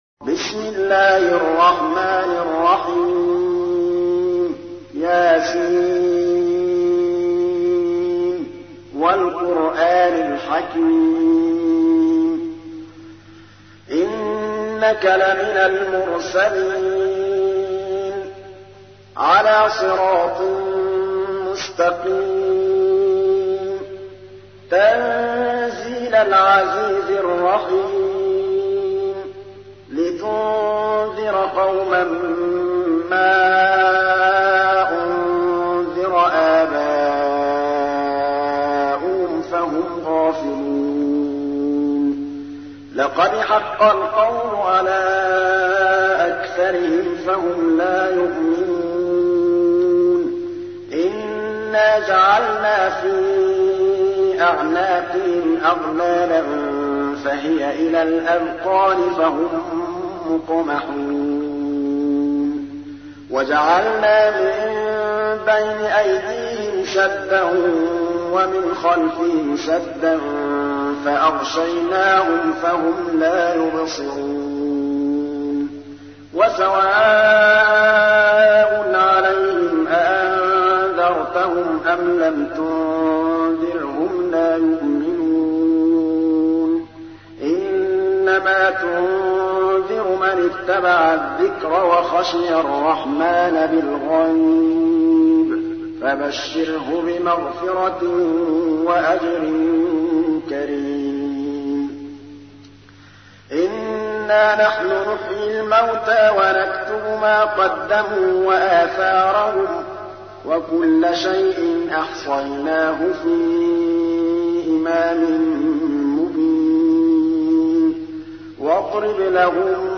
تحميل : 36. سورة يس / القارئ محمود الطبلاوي / القرآن الكريم / موقع يا حسين